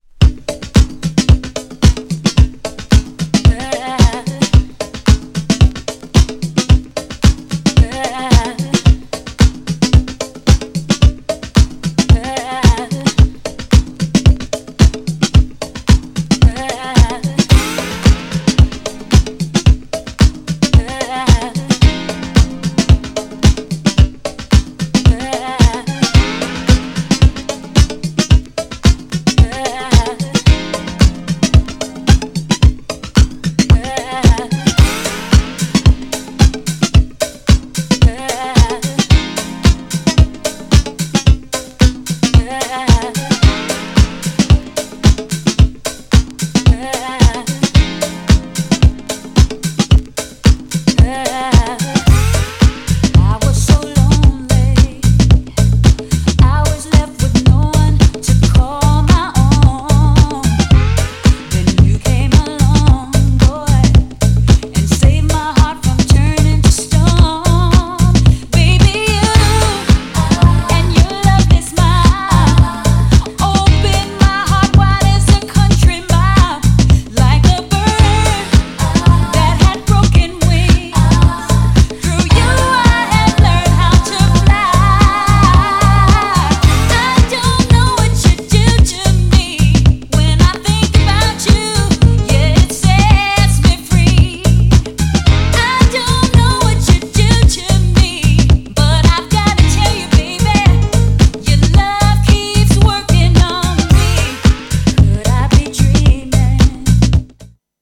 GENRE R&B
BPM 101〜105BPM
# GROOVYなR&B
# 女性VOCAL_R&B